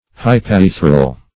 Hypaethral \Hy*p[ae]"thral\, Hypethral \Hy*pe"thral\, a. [L.